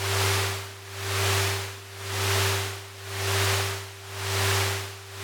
logo-whoosh.mp3